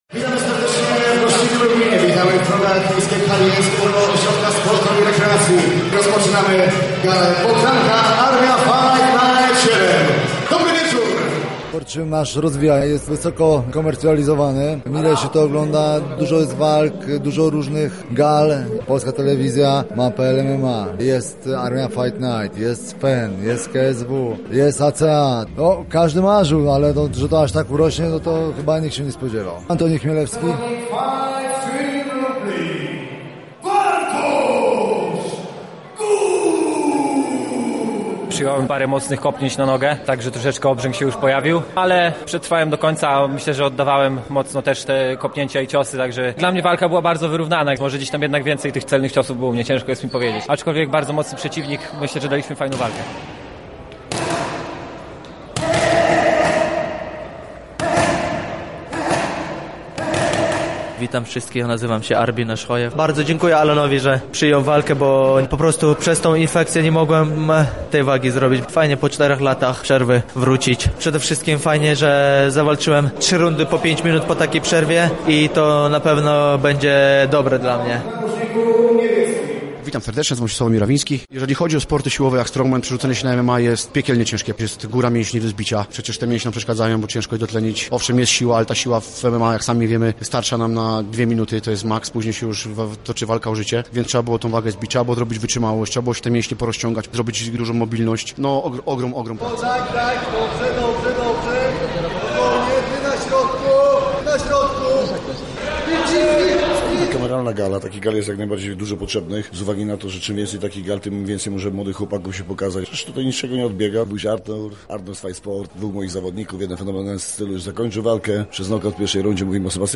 Gali towarzyszyło wiele emocji, o których opowiedzieli sami uczestnicy i organizatorzy.